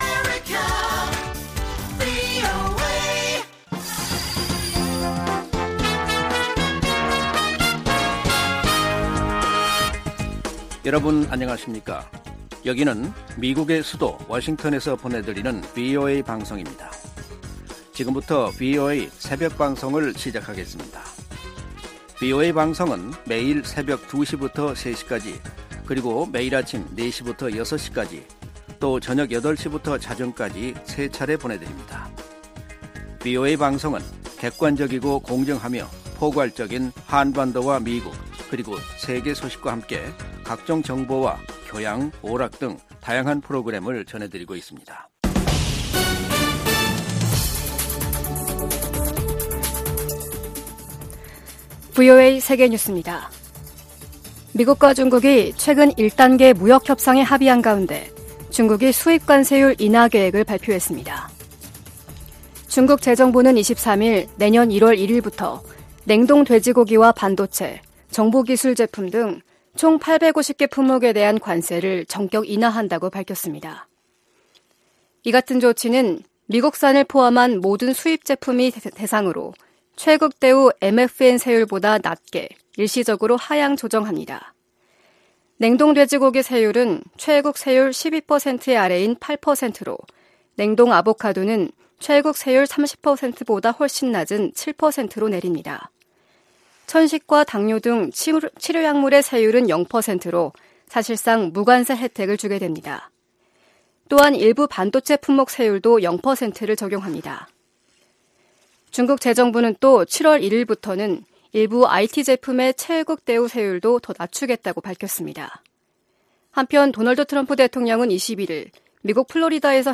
VOA 한국어 '출발 뉴스 쇼', 2019년 12월 24일 방송입니다. 중국을 방문한 한국의 문재인 대통령이 23일 시진핑 국가주석과 만나 한반도 정세 완화와 미-북 대화 재개 방안 등에 대해 의견을 나눴습니다. 마크 에스퍼 미국 국방장관은 북한 핵 문제의 외교적 해결을 바라지만, 미국은 싸워서 이길 준비도 돼 있다고 말했습니다.